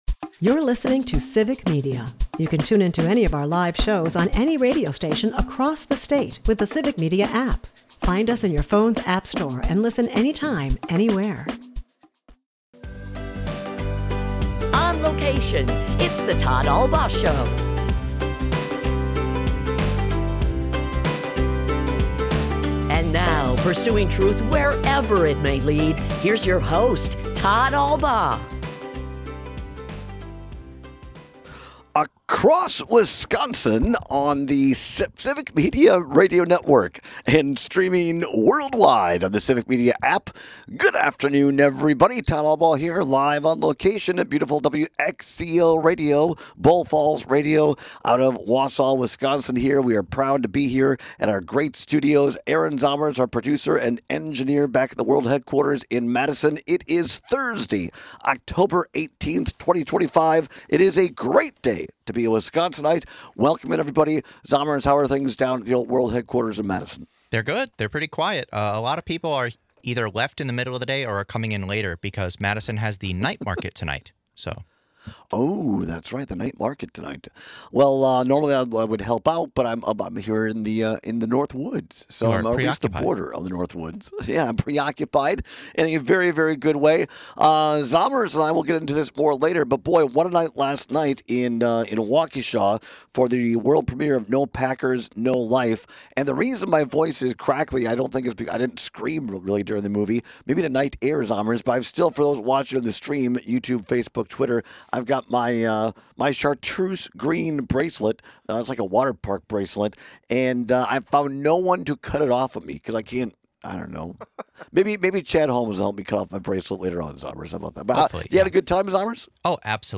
LIVE From WXCO: What’s Up In Wausau? (Hour 1) - Civic Media